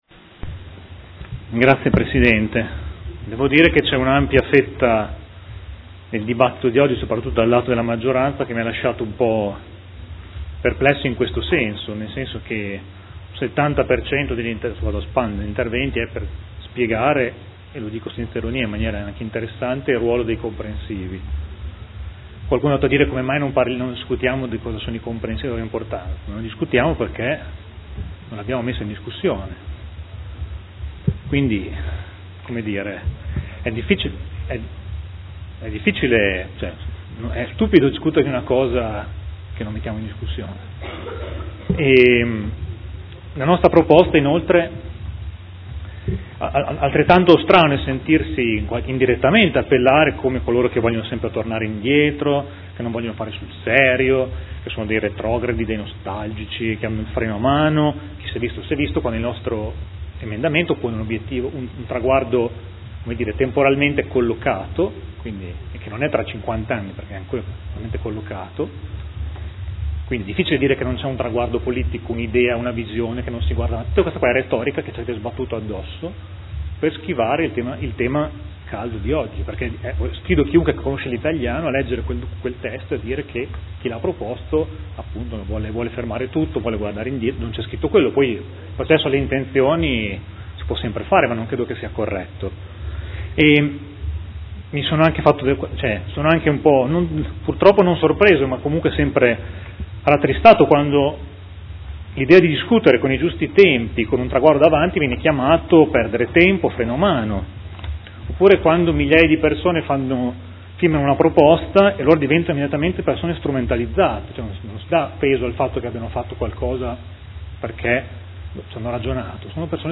Seduta del 26/11/2015 Dichiarazione di voto. Delibera: Riorganizzazione della rete scolastica e costituzione degli Istituti Comprensivi